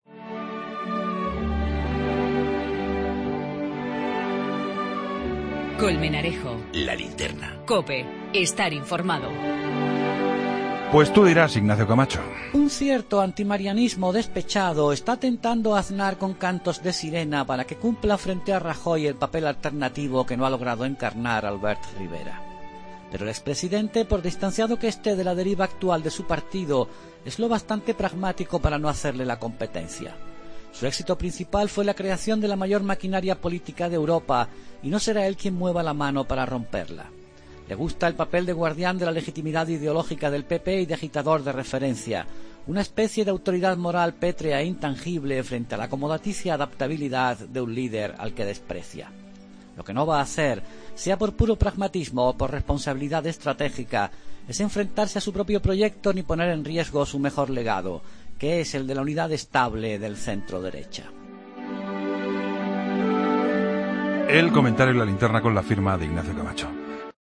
El comentario de Ignacio Camacho en 'La Linterna' sobre la polémica entre José María Aznar y el Partido Popular.